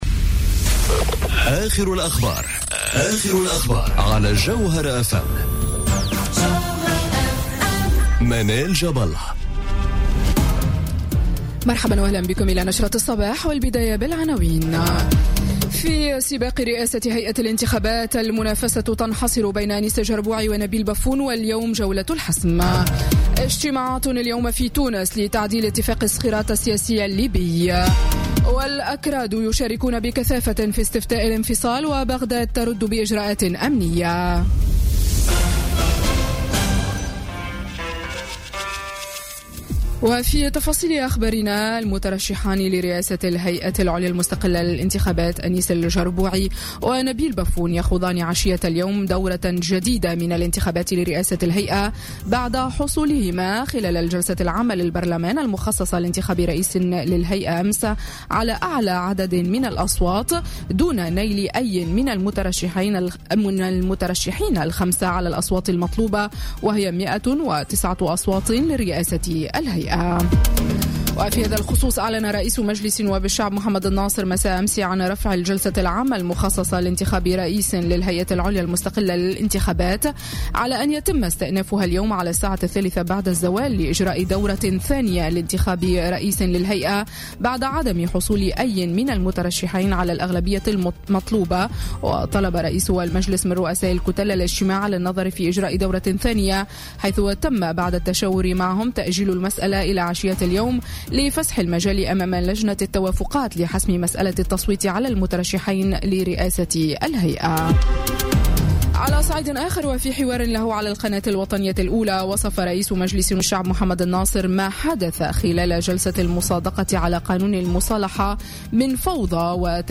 نشرة أخبار السابعة صباحا ليوم الثلاثاء 26 سبتمبر 2017